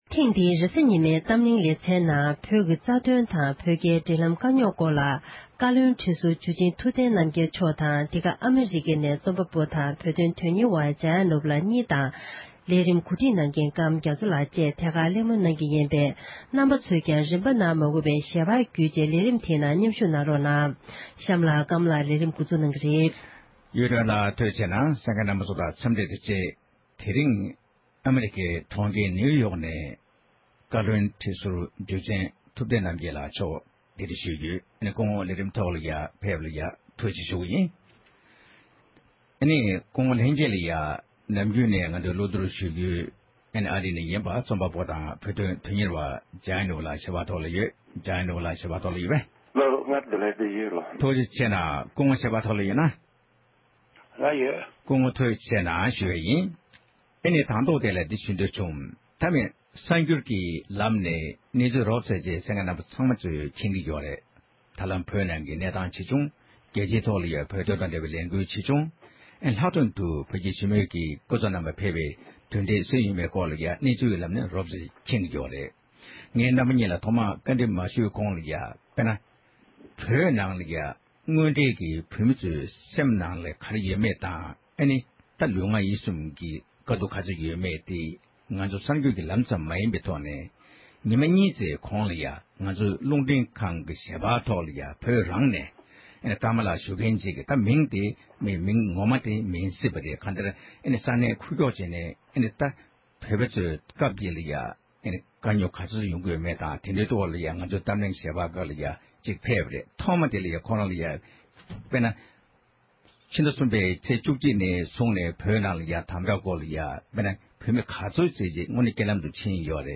བཀའ་བློན་ཁྲི་ཟུར་འཇུ་ཆེན་ཐུབ་བསྟན་རྣམ་རྒྱལ་མཆོག་ནས་བོད་རྒྱ་འབྲེལ་སྐོར་གླེང་མོལ་གནང་བ།